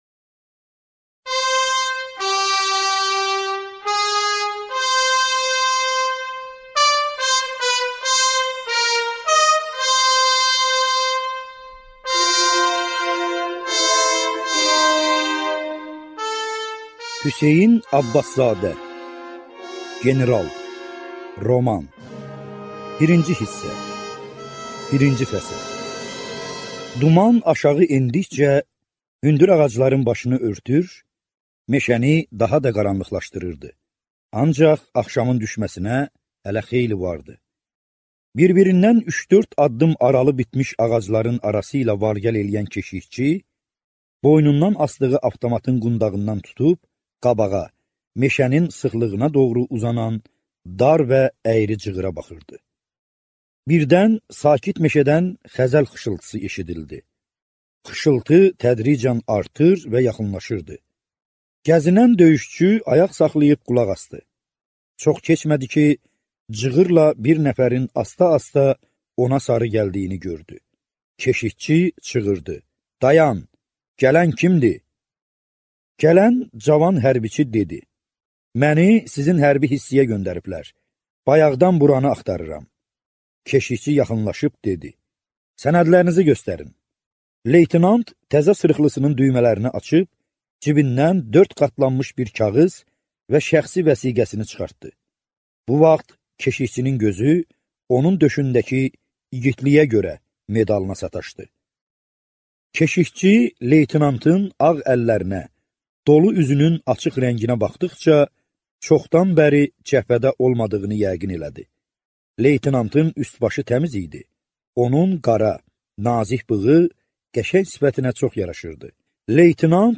Аудиокнига General | Библиотека аудиокниг